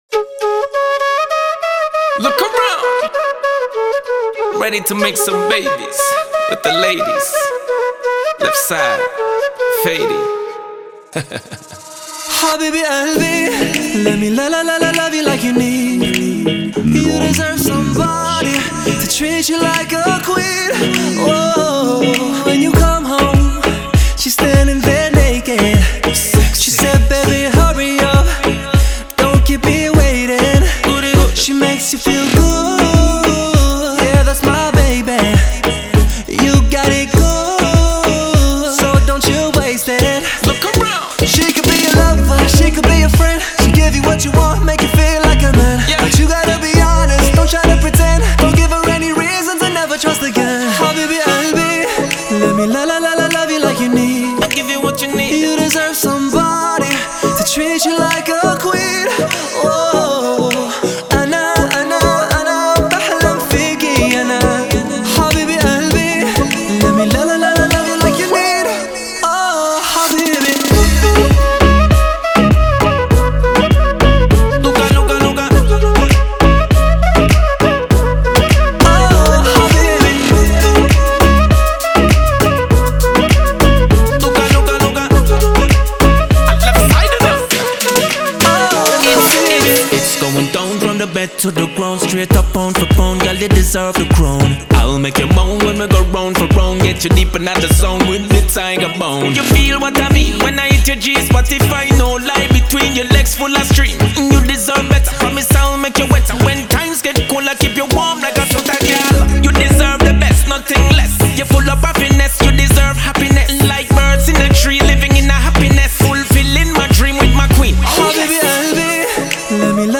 Download Arabic dance remix